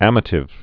(ămə-tĭv)